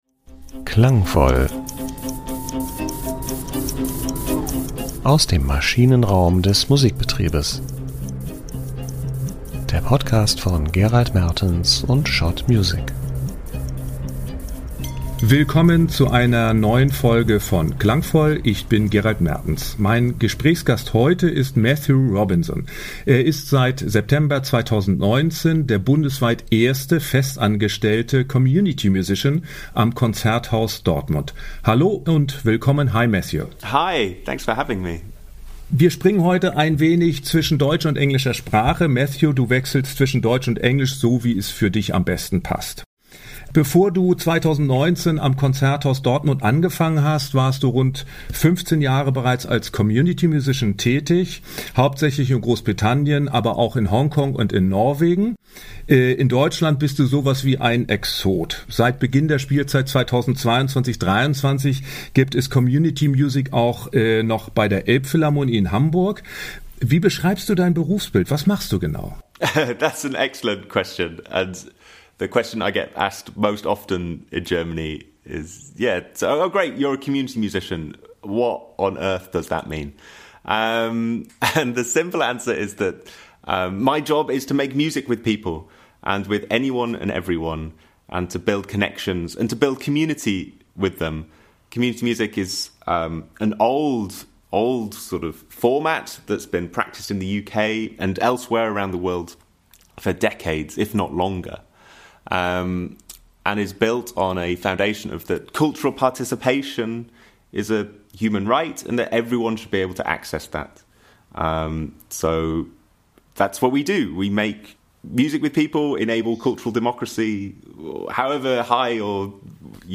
Im Gespräch beschreibt er, wie das gelingen kann (deutsch/englisch).